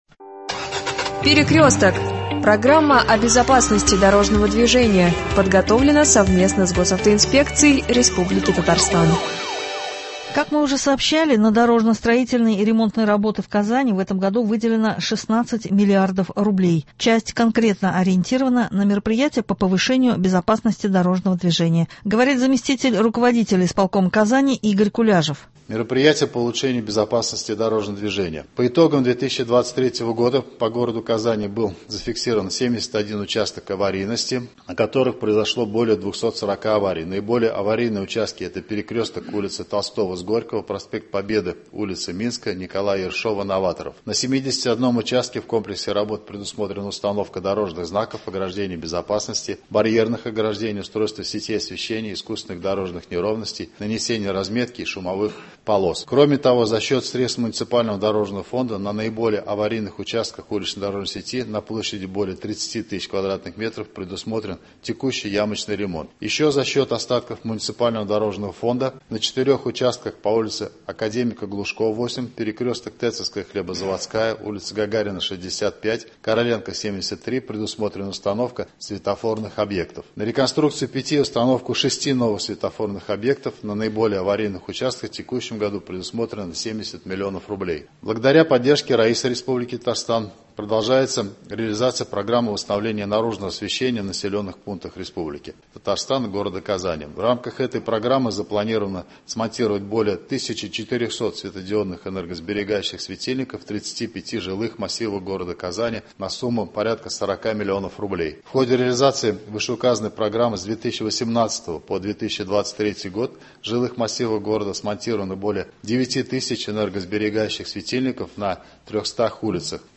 Говорит зам рук исполкома Казани Игорь Куляжев